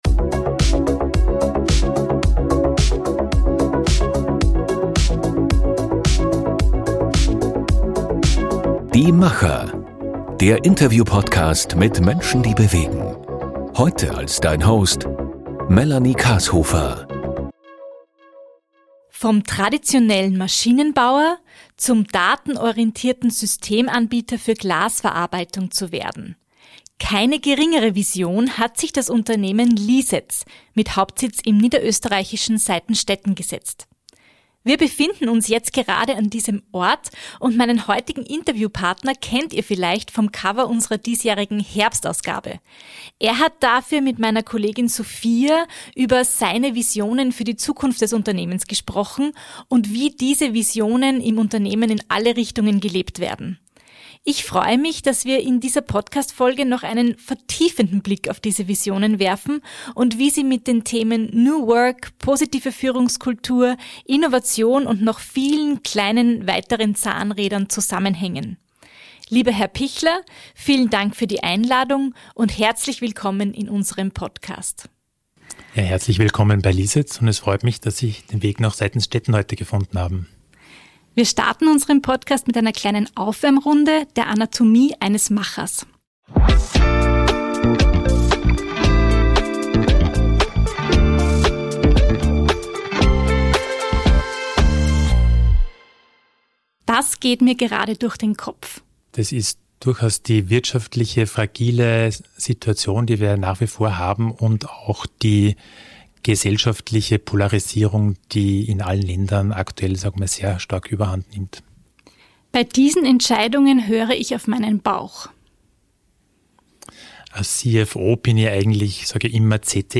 Für diese Podcastfolge besuchen wir das Unternehmen vor Ort und unseren Interviewgast kennt ihr vielleicht vom Cover unserer diesjährigen Herbstausgabe